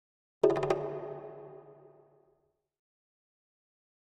Jungle Drums In The Middle Of The Jungle - Slow Down Thin Hits 3 - Short